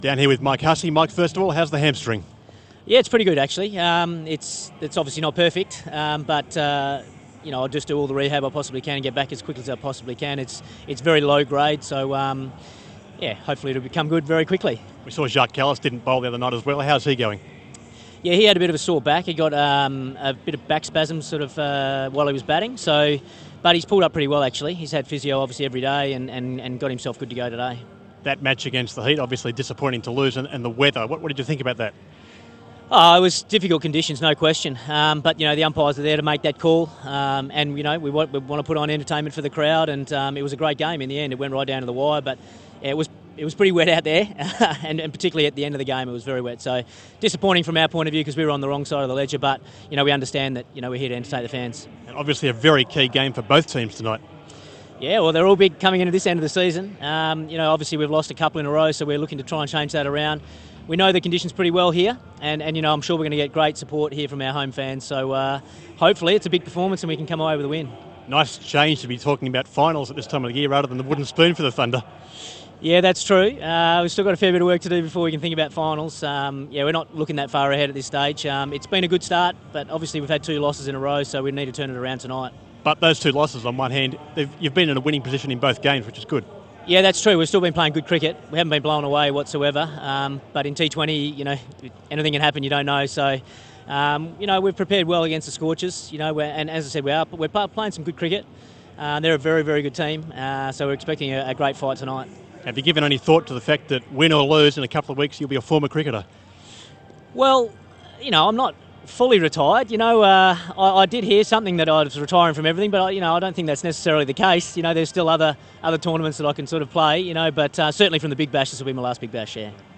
INTERVIEW: Thunder captain Mike Hussey speaks about hamstring injury